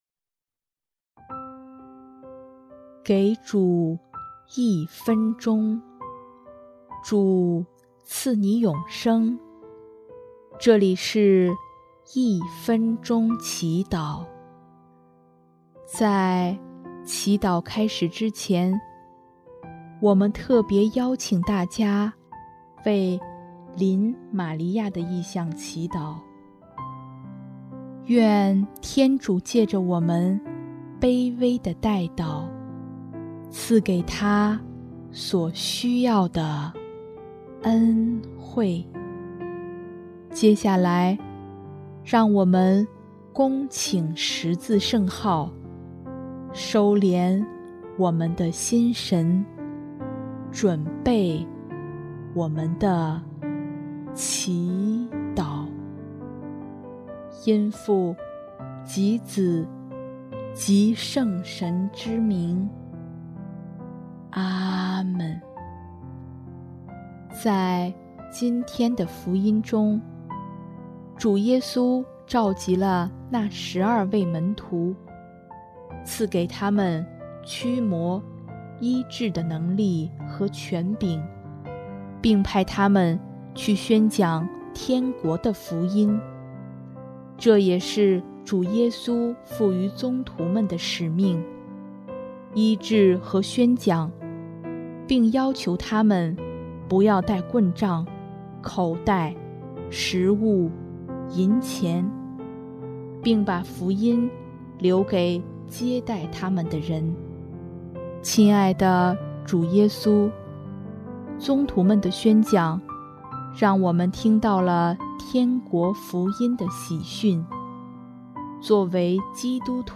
音乐：第一届华语圣歌大赛参赛歌曲《撇下》